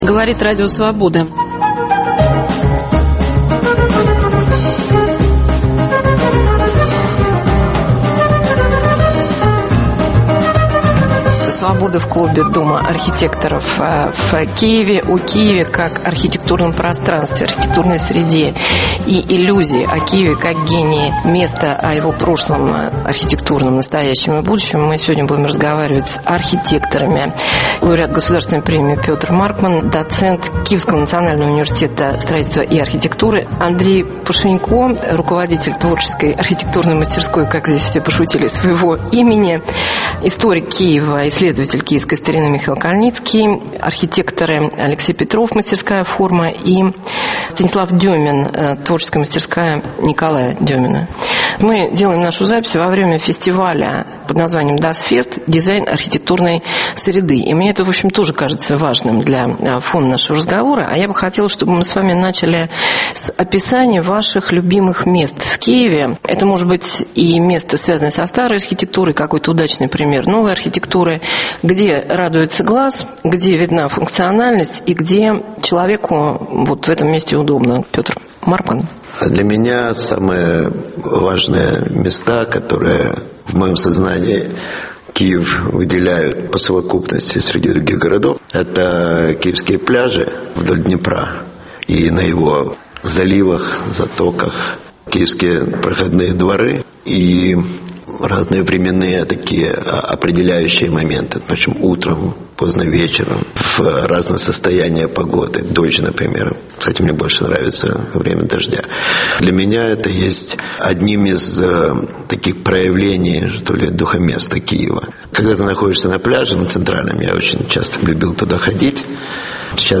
Свобода в клубе Дома архитектора в Киеве.